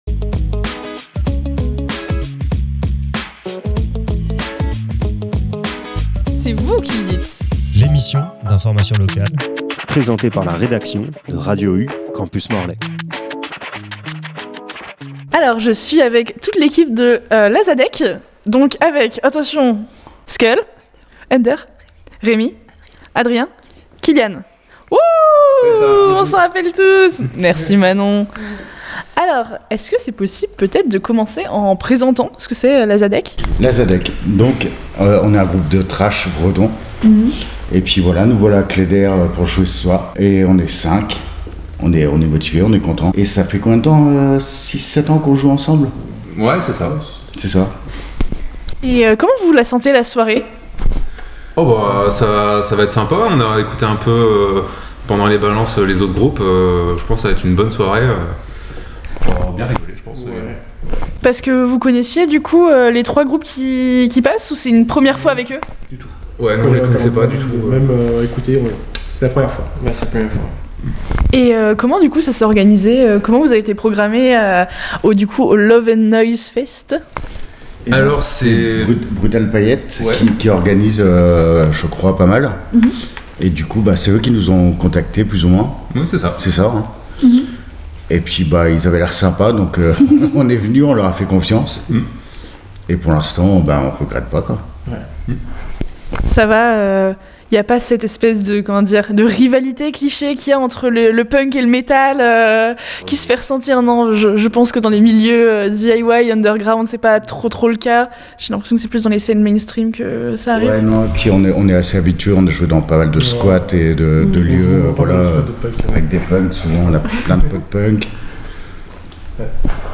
Lors du festival Love and Noise se déroulant à Cléder le samedi 14 février, nous avons pu faire la connaissance du groupe Lazhadek. Entre passion, sincérité et esprit collectif, Lazhadek partage sa vision de la scène locale et l’importance des festivals comme Love and Noise pour faire vivre la musique indépendante.